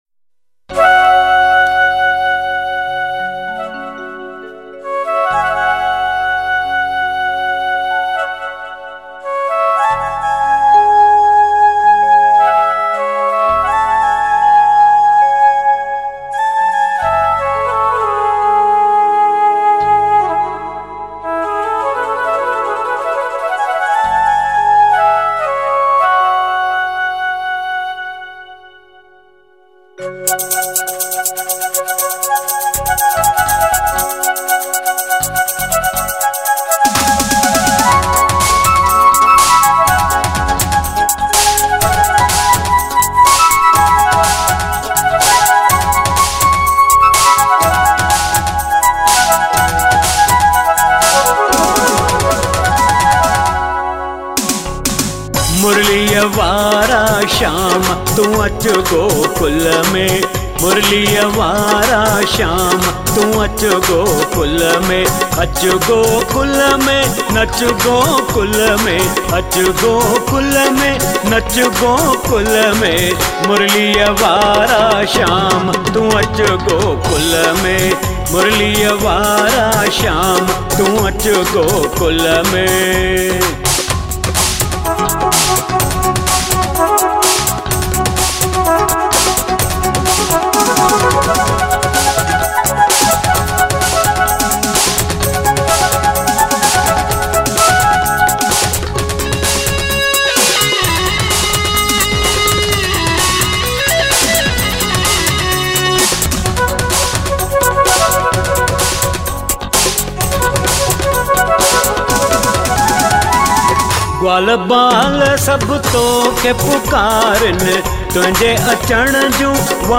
Sindhi Bhajans